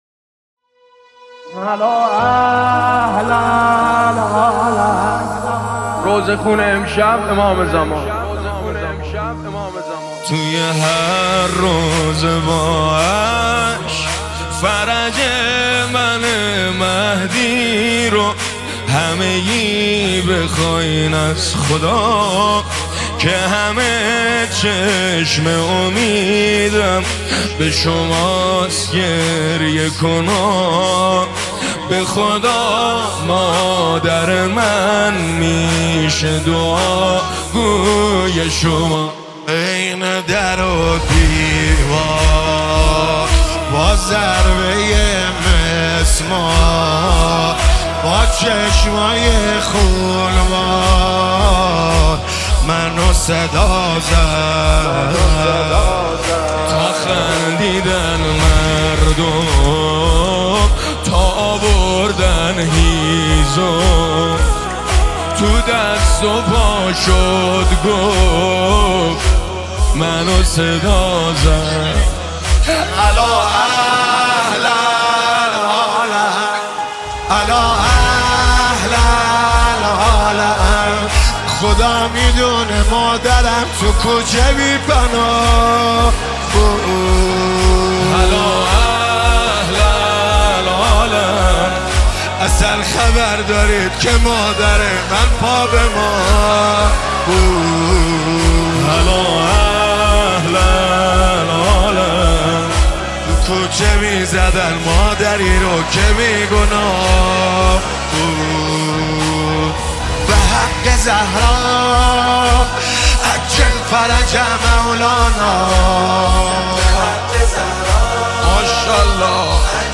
مداحی فاطميه